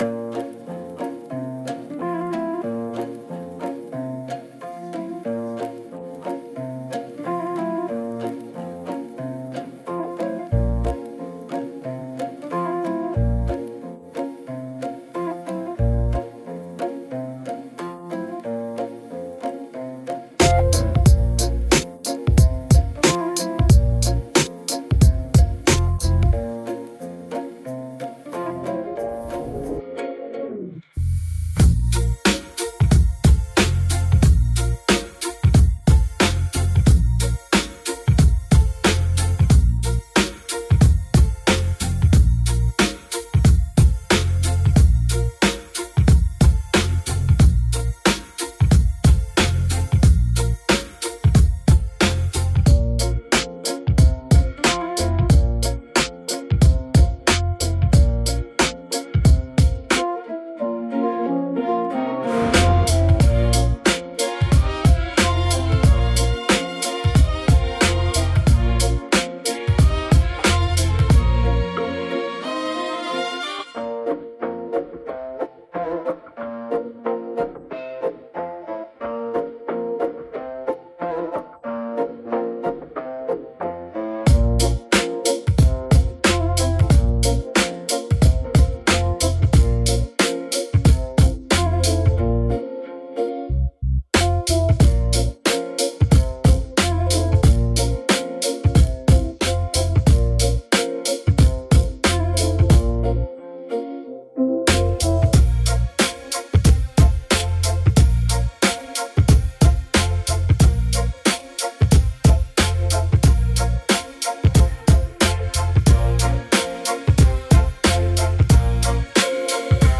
#16 — (Instrumental)